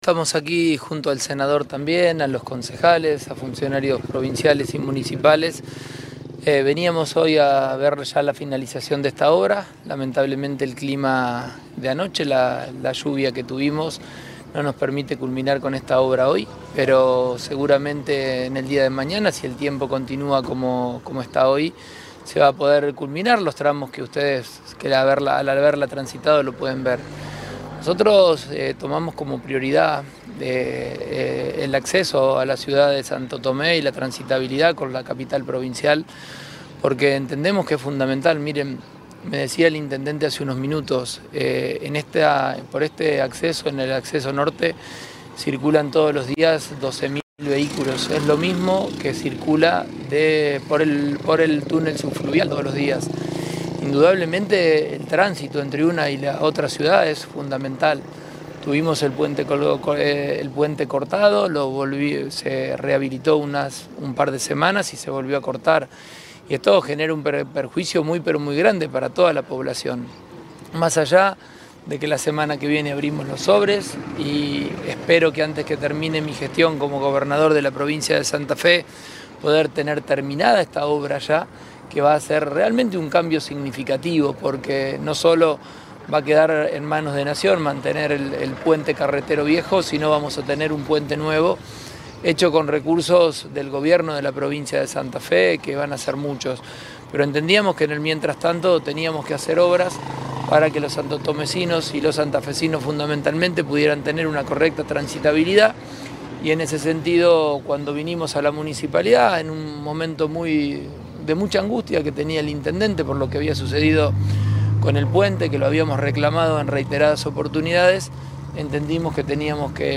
Declaraciones del Gobernador